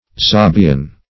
zabian - definition of zabian - synonyms, pronunciation, spelling from Free Dictionary
zabian - definition of zabian - synonyms, pronunciation, spelling from Free Dictionary Search Result for " zabian" : The Collaborative International Dictionary of English v.0.48: Zabian \Za"bi*an\, a. & n. See Sabian .